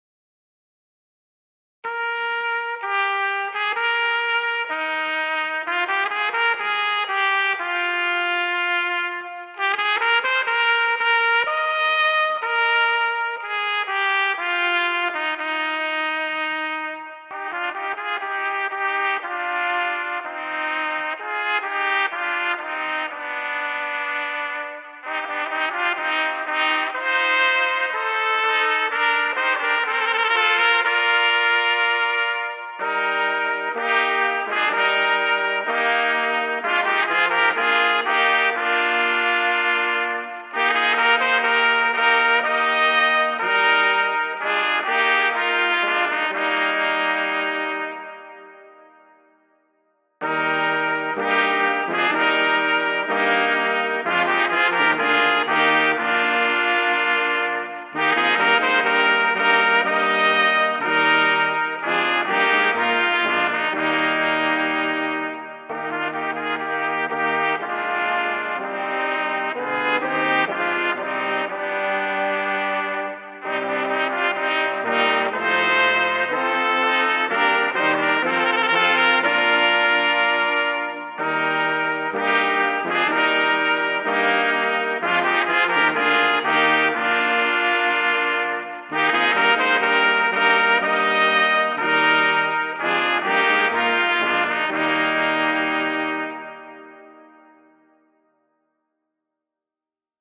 Posaunenchor
Hörprobe unseres Posaunenchores; Tochter Zion
Tochter_Zion_4stimmig.mp3